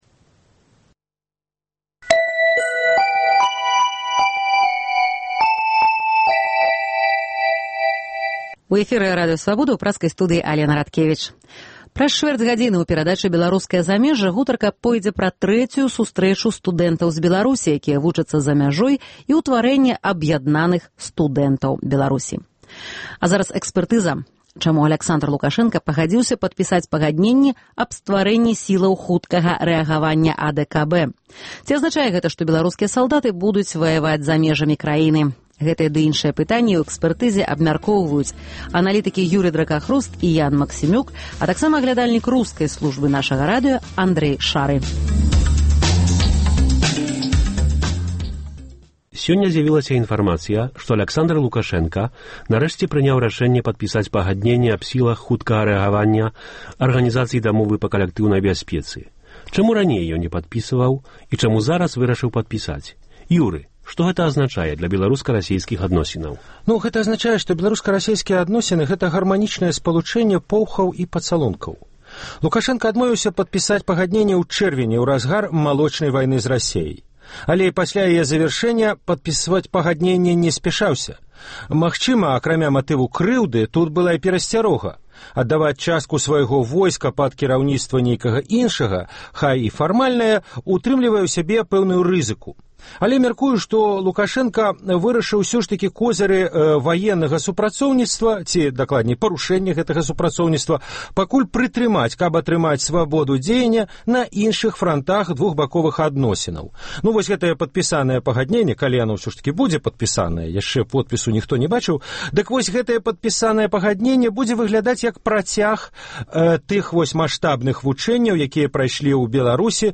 Гэтыя пытаньні ў перадачы “Экспэртыза Свабоды” абмяркоўваюць: аналітыкі